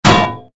AA_drop_anvil_miss.ogg